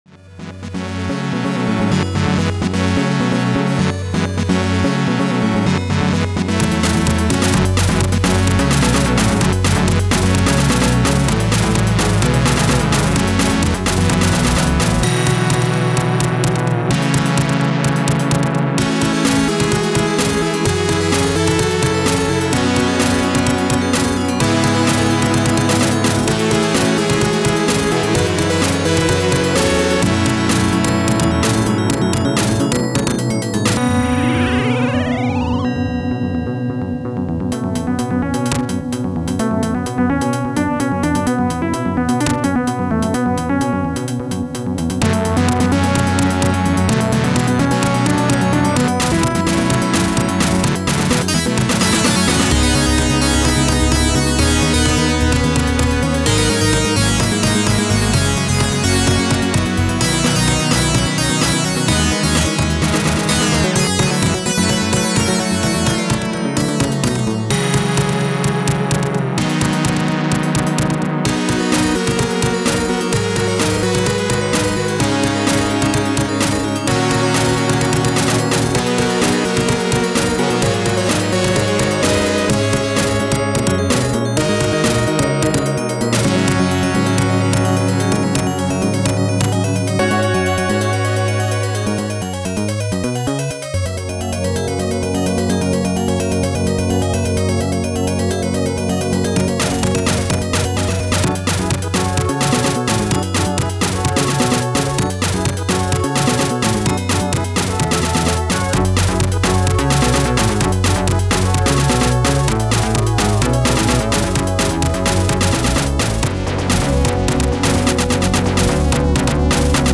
Not too much to say here, its pretty short, but I like the homely, cozy nature of this song, hence I gave it this title :3 Youtube Upload